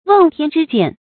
瓮天之见 wèng tiān zhī jiàn
瓮天之见发音